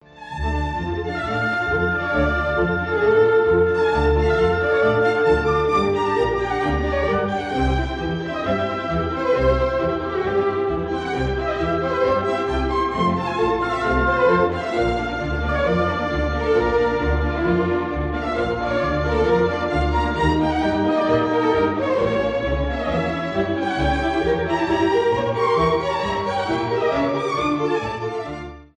без слов , инструментальные
классические